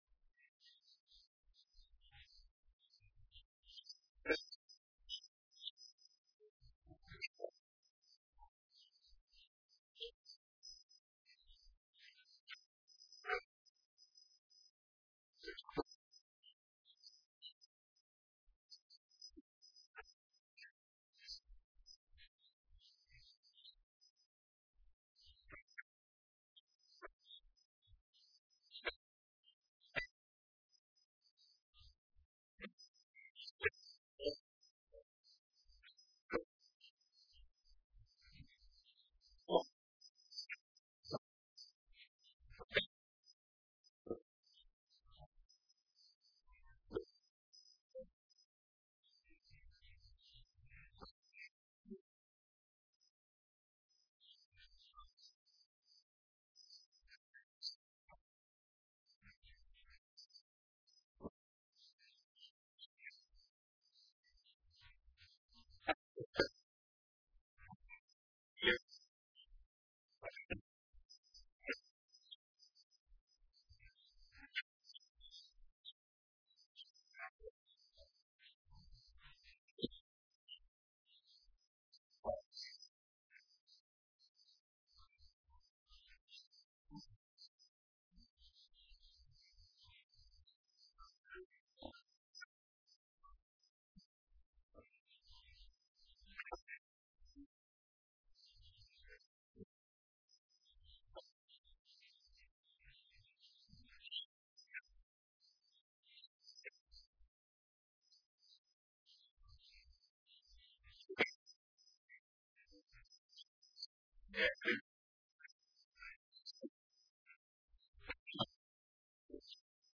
Online Sermons at St. Pauls